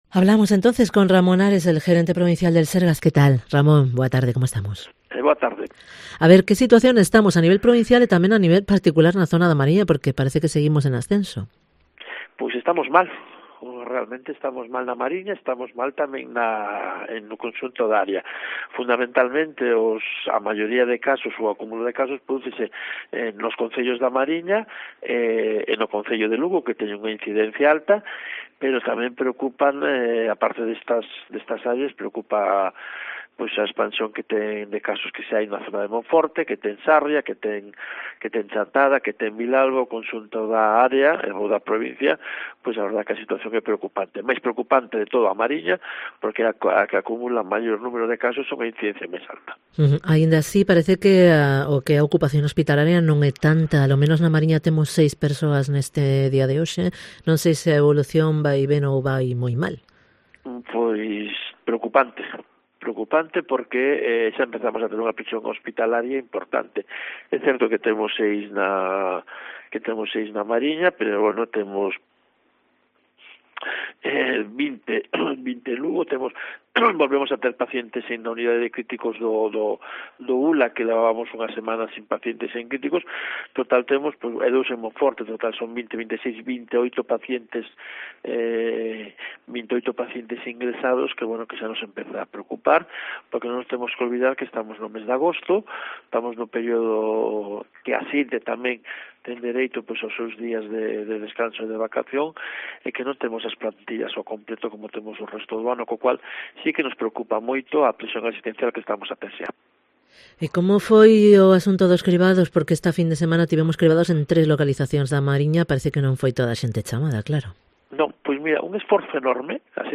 Entrevista a Ramón Ares, gerente provincial del SERGAS